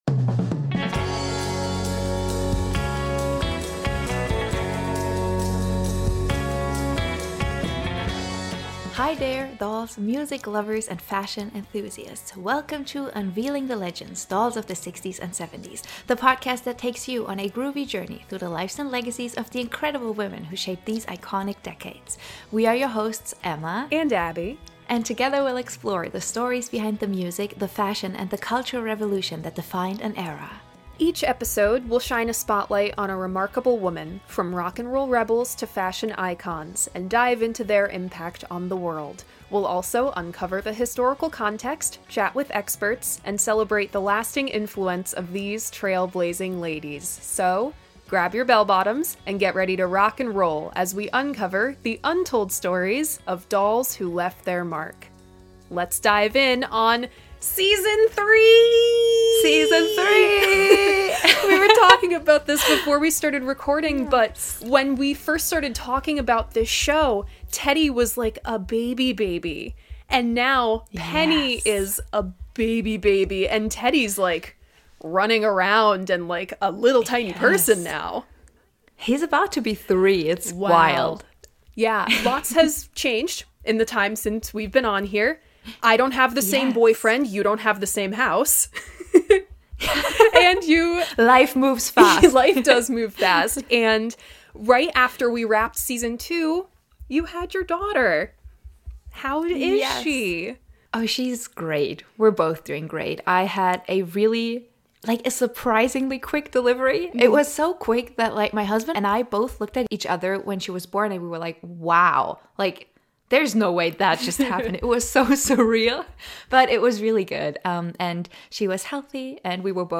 Jennifer Juniper: An Interview with Jenny Boyd